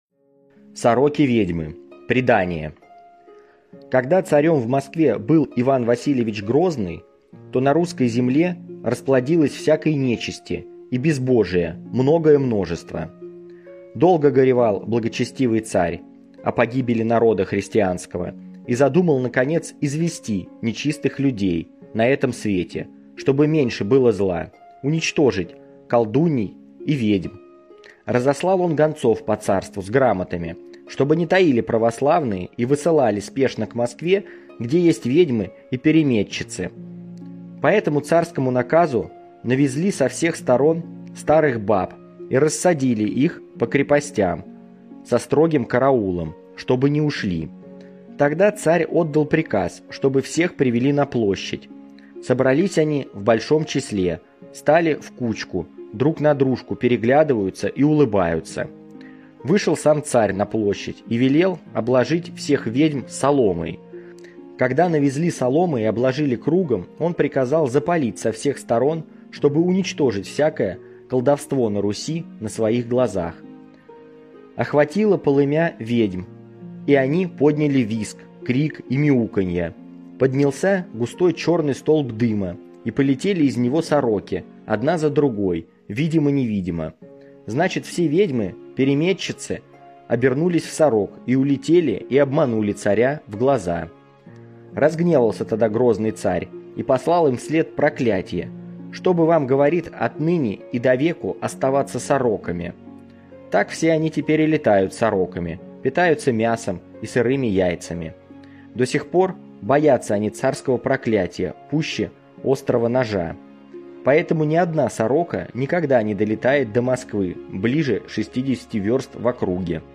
Аудиосказка «Сороки-ведьмы»